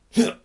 行动 " 硬踢
标签： 战斗 战斗 战斗 战斗 冲击
声道立体声